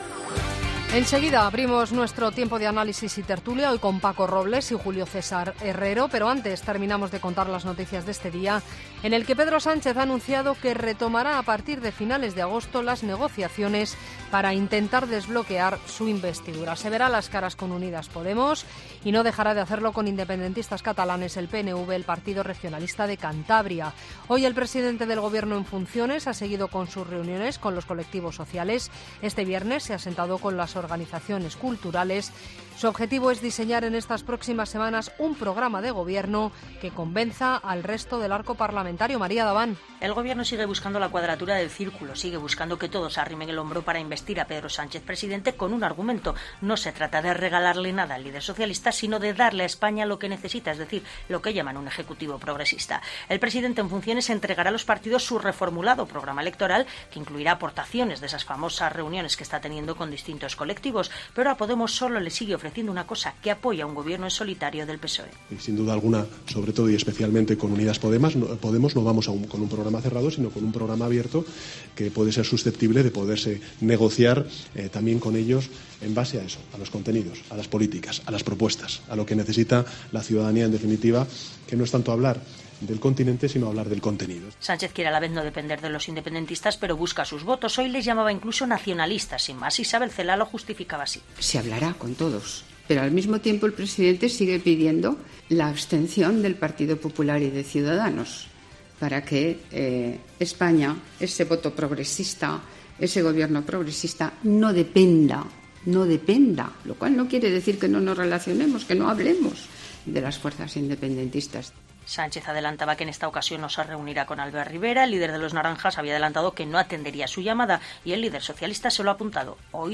Boletín de noticias de COPE del 9 de agosto de 2019 a las 22.00 horas